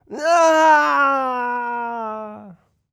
Male_Falling_Shout_04.wav